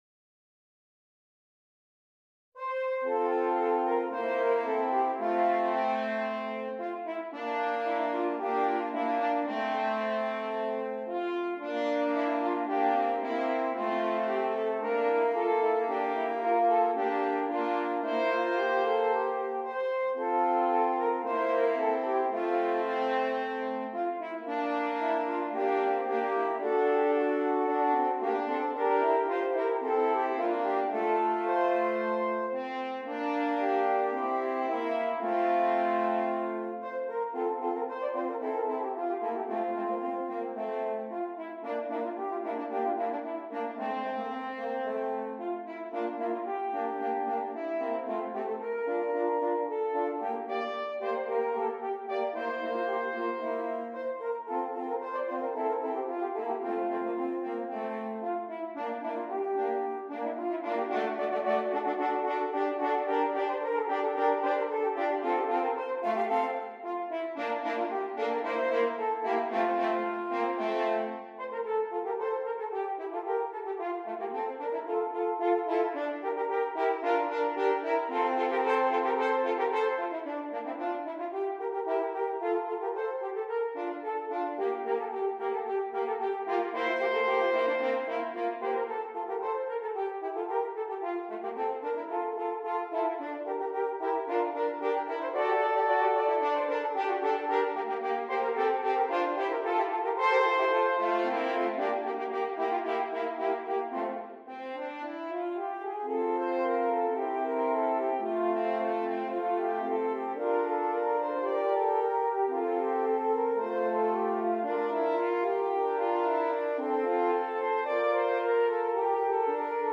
3 F Horns